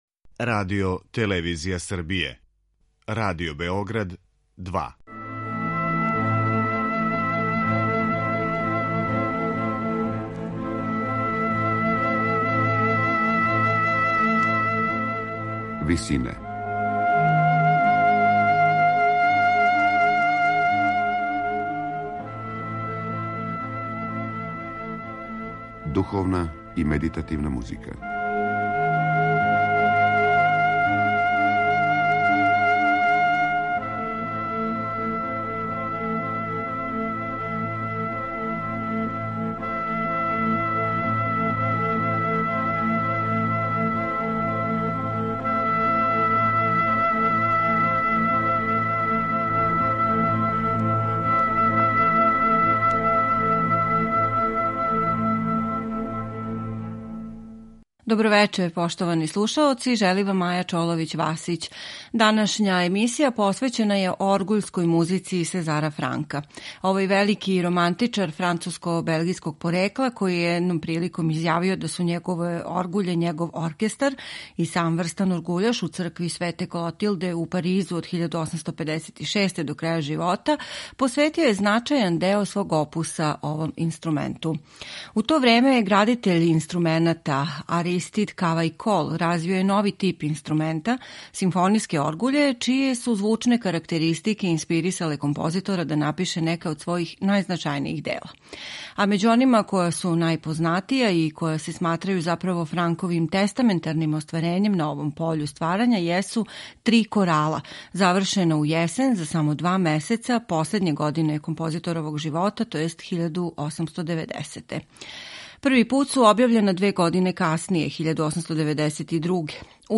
Узвишена, свечана и религиозна атмосфера, богатство хармоније и боја, као и широки динамички распон, упечатљивост варијационог процеса и преображавања основног тематког матерјала - само су неке од каркатеристика ових грандиозна комада несумњивог симфонијског карактера које ћете слушати у извођењу Мери Kлер Ален.